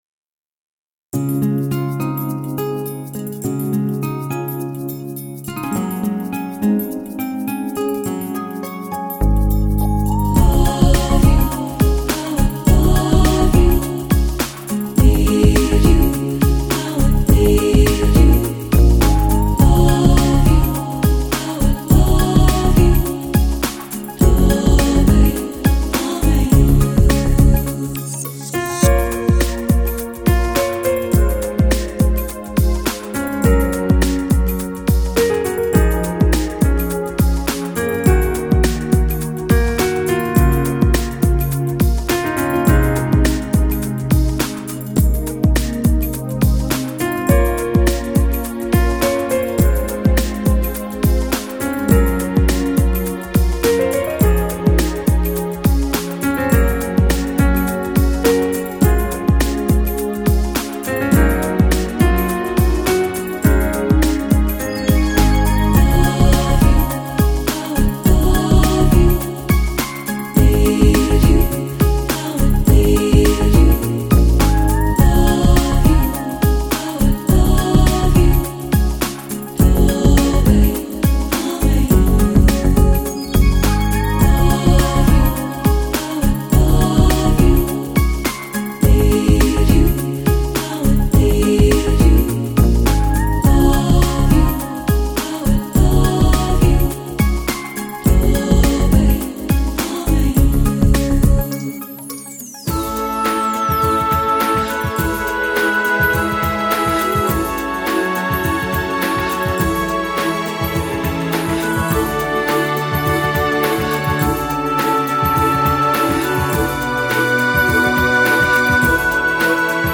风格: Contemporary Celtic
将传统居尔特民谣音乐赋予电子新生命